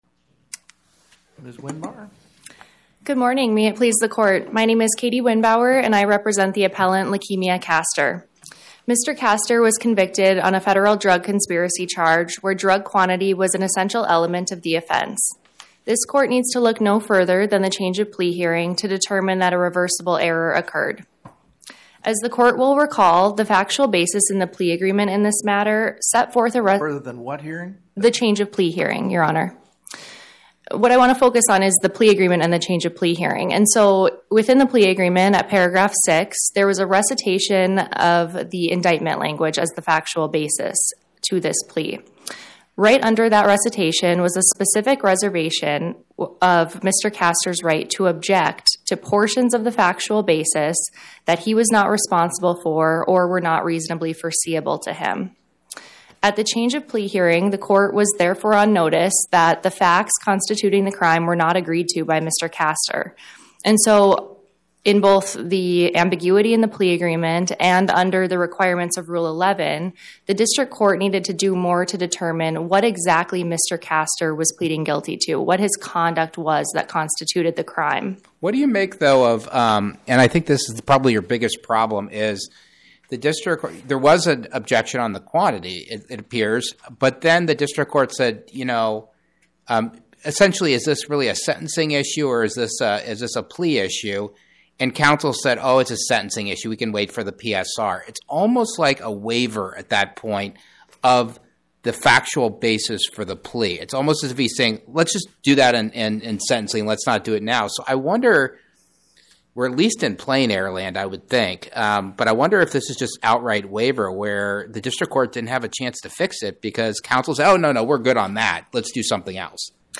Oral argument argued before the Eighth Circuit U.S. Court of Appeals on or about 02/13/2026